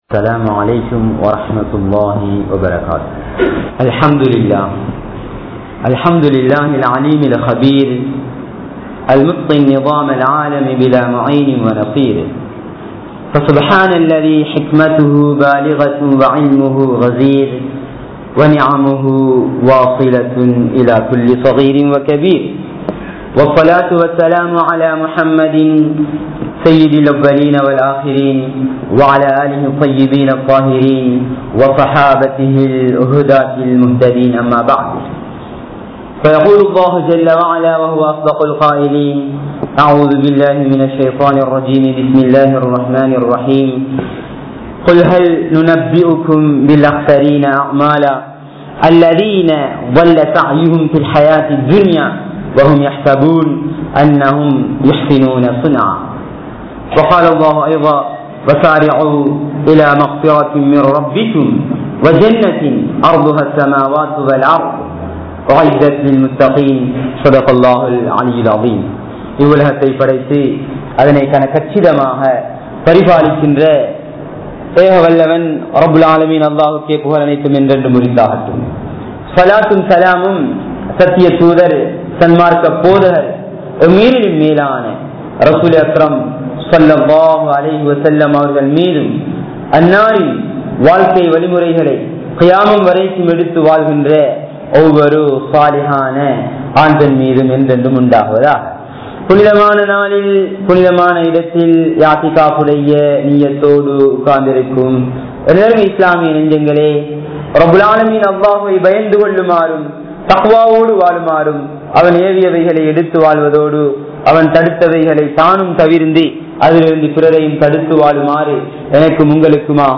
Ramalan (ரமழான்) | Audio Bayans | All Ceylon Muslim Youth Community | Addalaichenai
Thaqwa Jumua Masjith